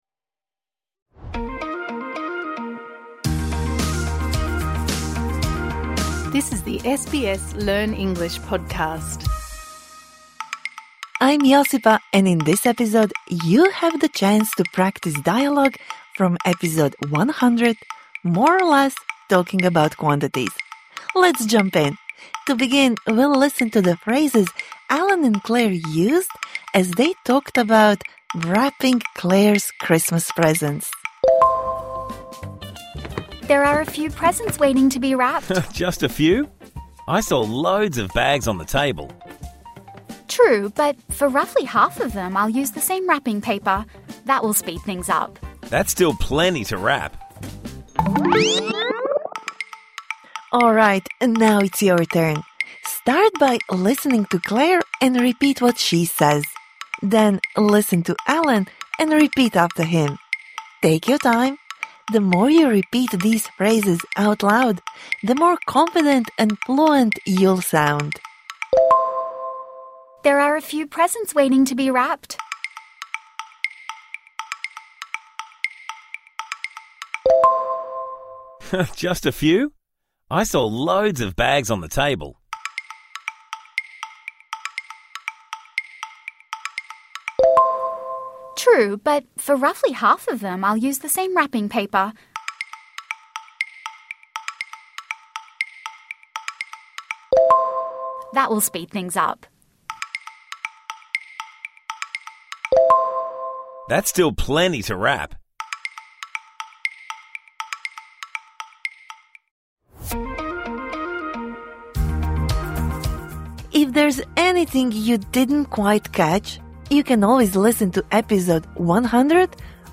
تدرّب على التحدّث من خلال حوار الحلقة 100: التحدّث عن الكميّات